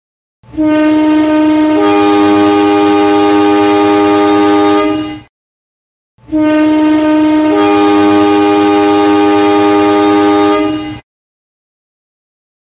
Suara Klakson Kereta Api Indonesia
Kategori: Suara Kendaraan
Keterangan: Download Suara Klakson Kereta Api Indonesia, Nikmati sound effect horn train CC 203 yang legendaris dan ikonik.
suara-klakson-kereta-api-indonesia-id-www_tiengdong_com.mp3